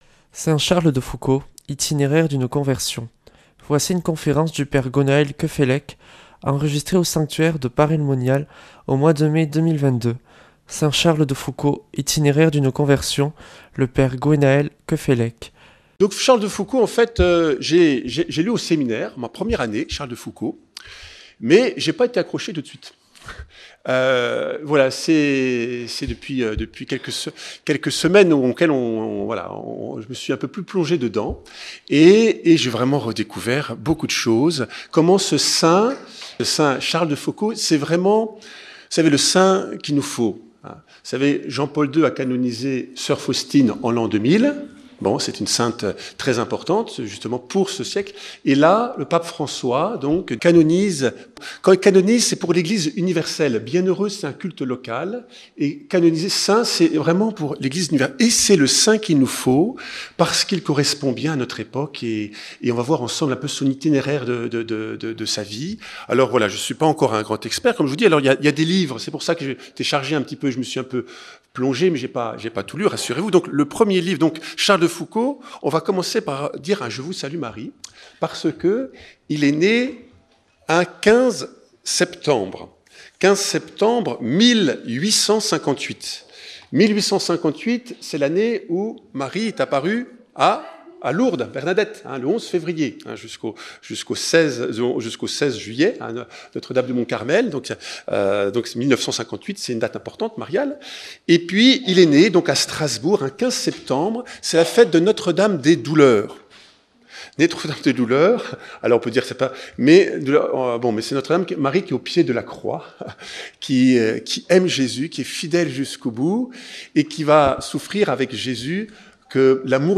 (Enregistré en mai 2022 à Paray-le-Monial)